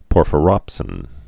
(pôrfə-rŏpsĭn)